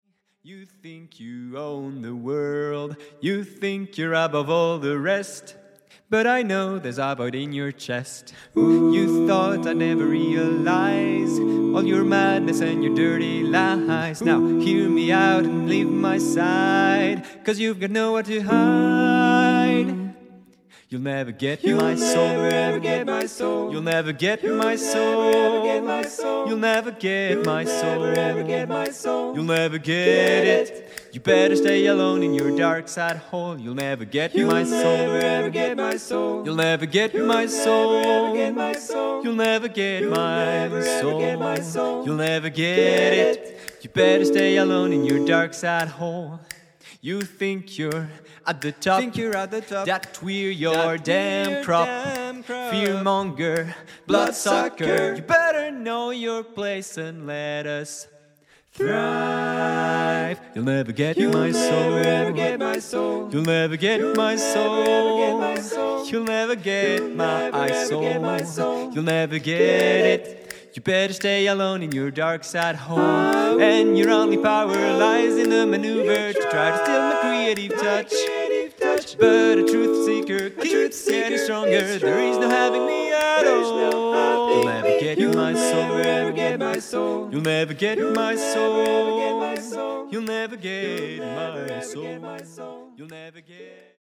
Composition originale pour chœur et soliste
Extrait audio (voix réelles)